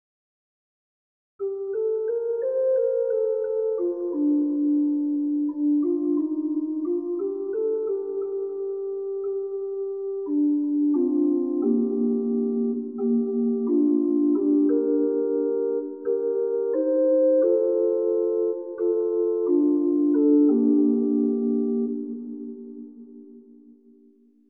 Antienne selon le Nouveau Lectionnaire